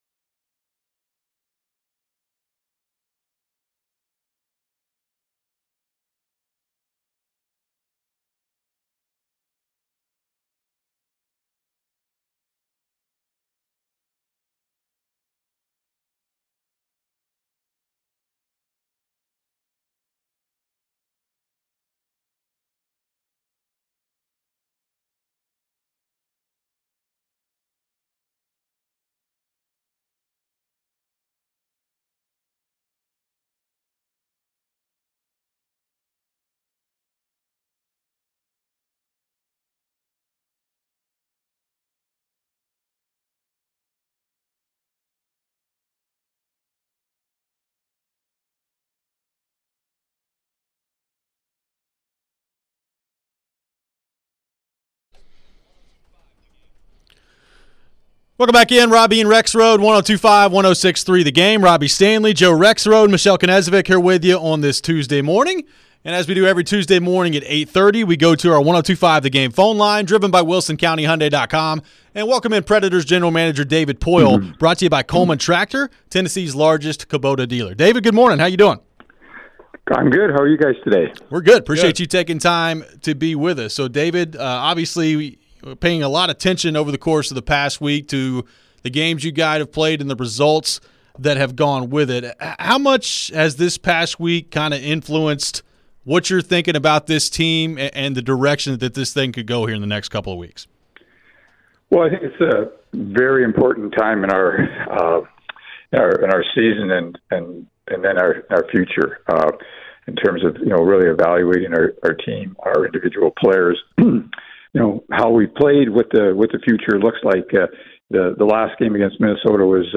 Interview with David Poile